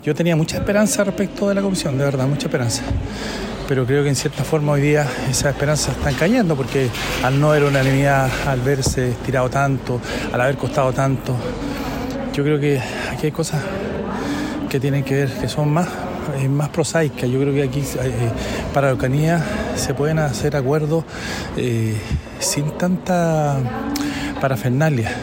En tanto, el presidente de Amarillos, diputado Andrés Jouanett, precisó que las expectativas que existían sobre el trabajo de la citada Comisión, se han diluido.